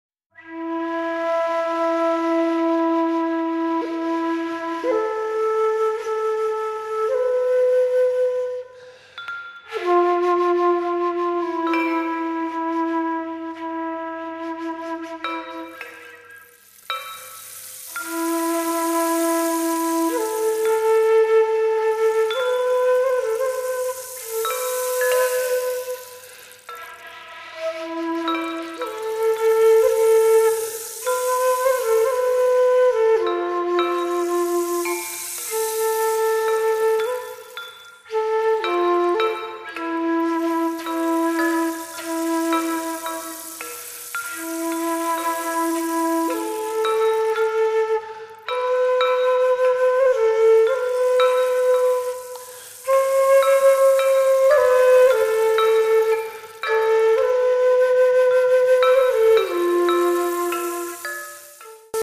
at studio Voice
尺八運指笛(陶器)、備長炭